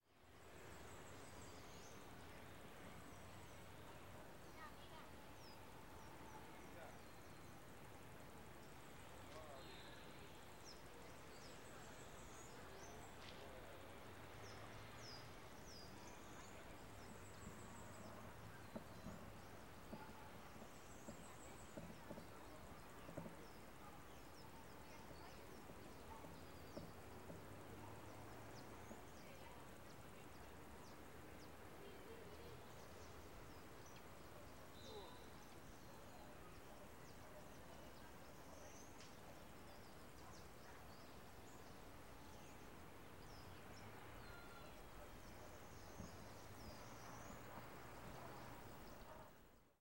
AMB tuberia rota
描述：broken piperecorded with me66procesed with live ableton
标签： backgroundsound soundscape ambience ambient background atmo field recording ambiance generalnoise atmosphere atmos
声道立体声